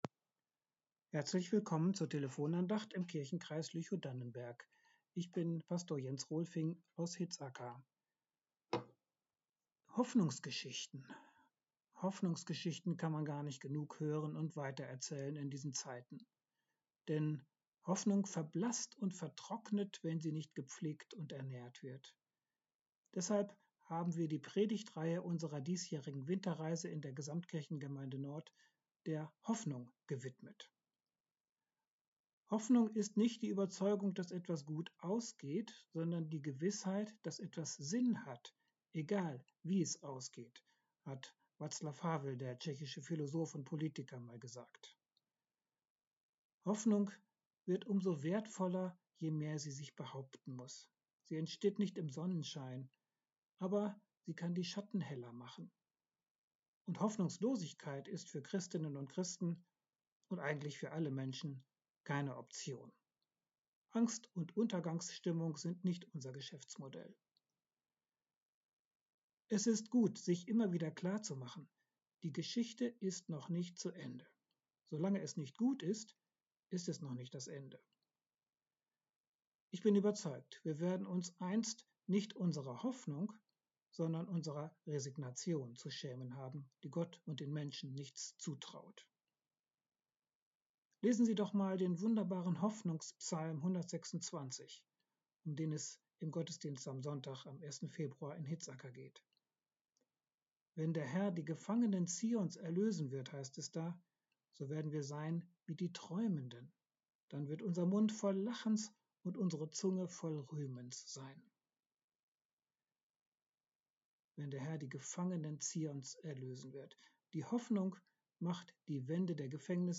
Die Geschichte geht immer weiter ~ Telefon-Andachten des ev.-luth. Kirchenkreises Lüchow-Dannenberg Podcast